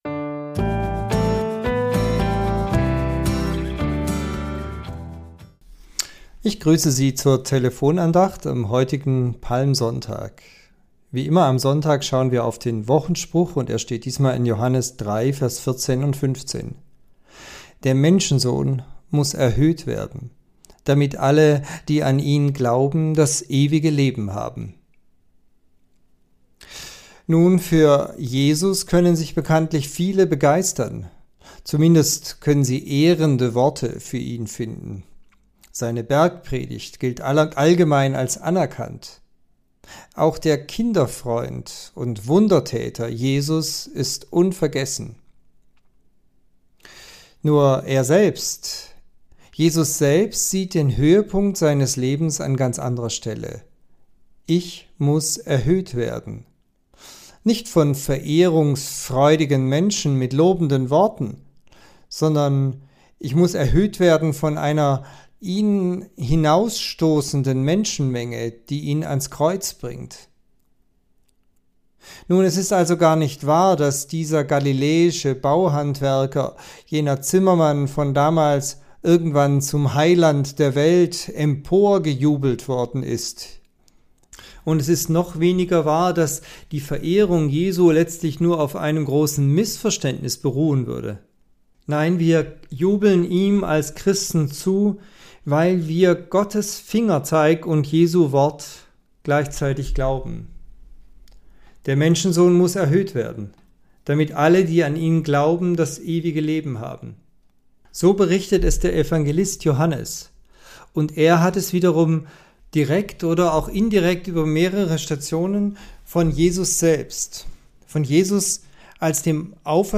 Andacht zum Wochenspruch (Sonntag Lätare)